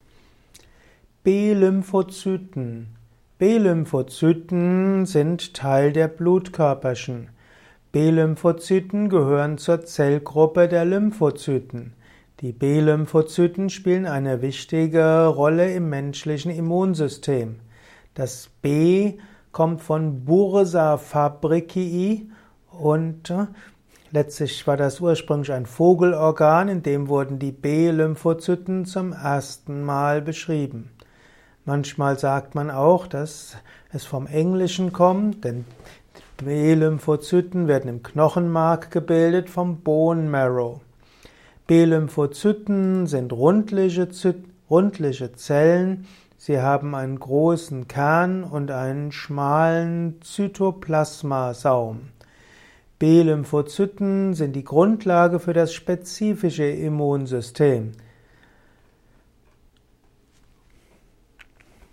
Kompakte Informationen zu B-Lymphozyten in diesem Kurzvortrag